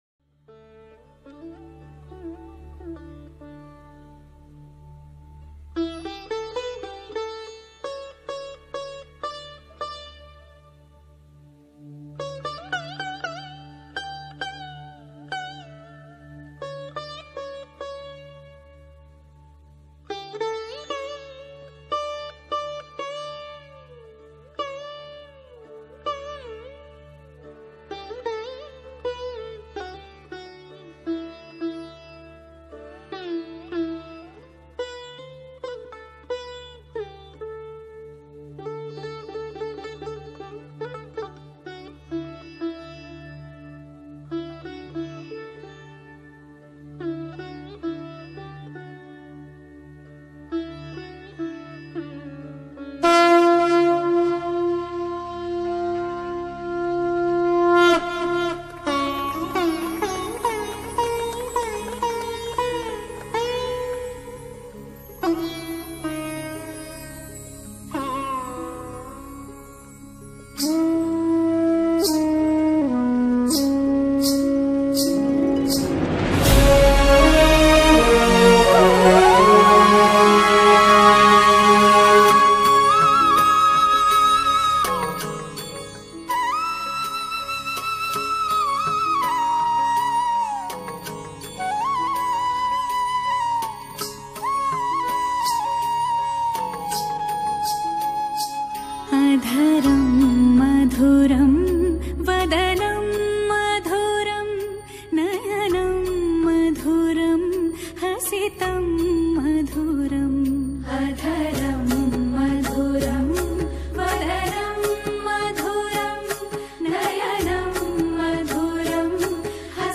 Releted Files Of Devotional